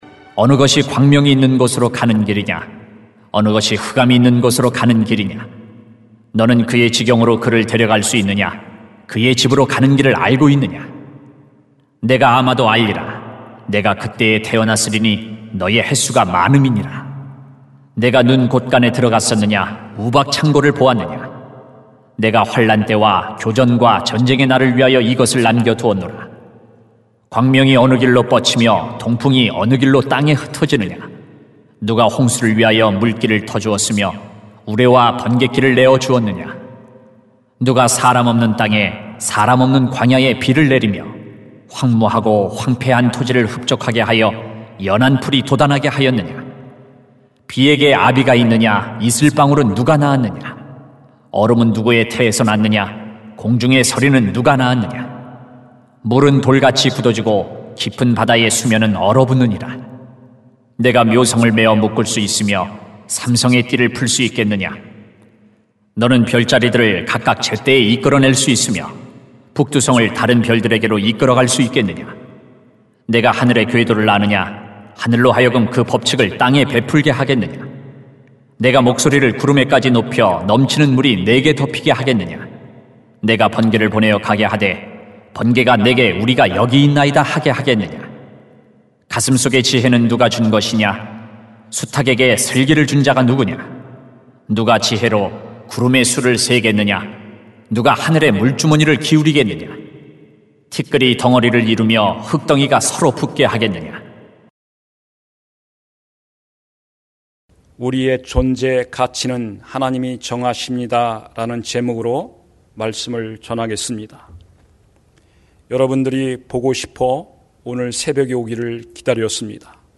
[욥 38:19-38] 우리의 존재 가치는 하나님이 정하십니다 > 새벽기도회 | 전주제자교회